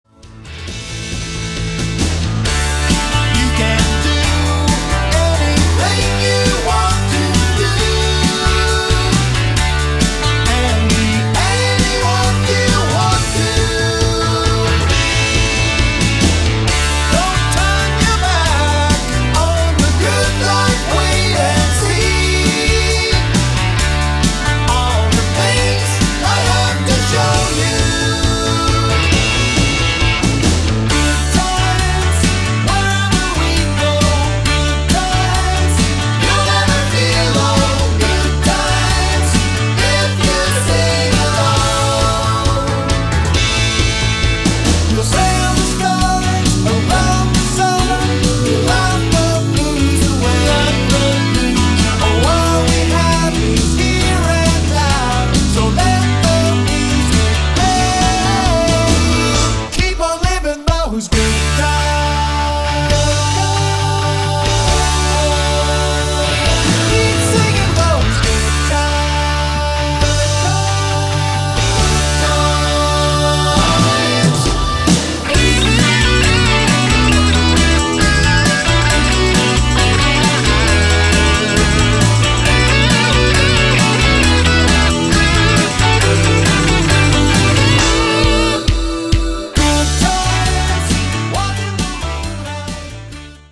Also featuring special guest singers